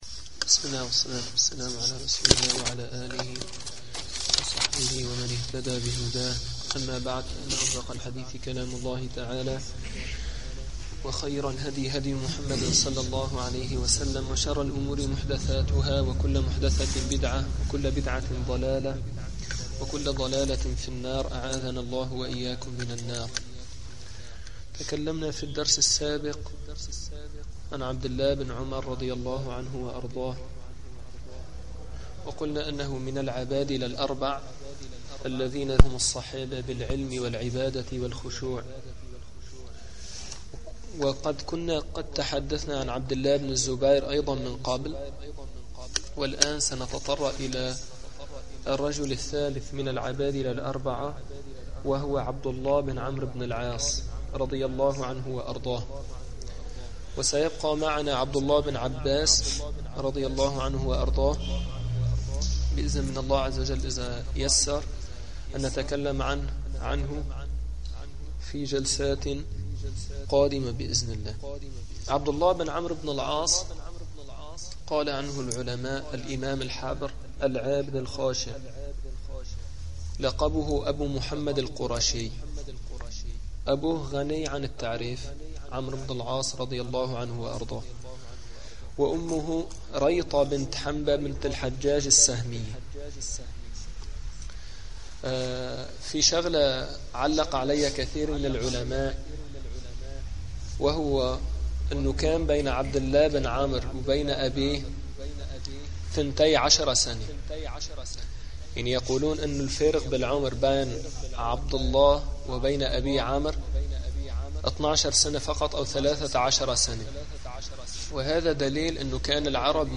من دروس مسجد القلمون الغربي الشرعية